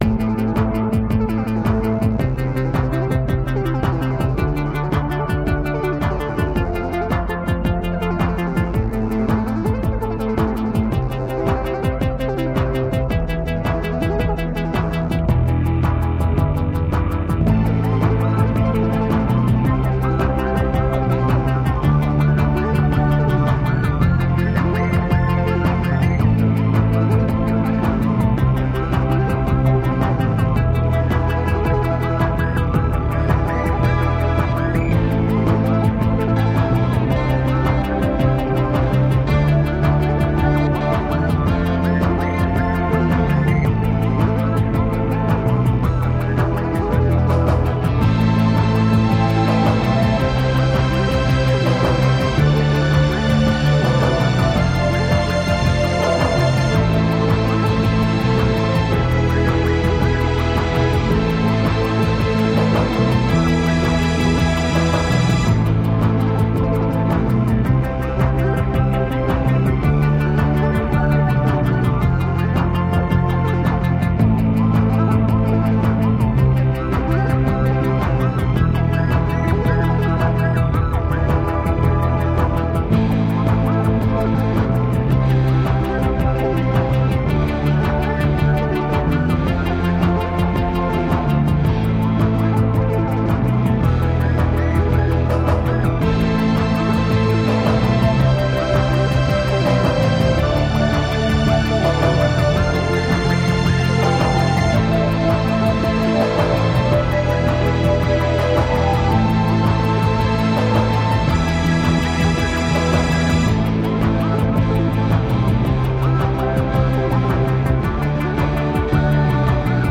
Tagged as: Electronica, Rock, Instrumental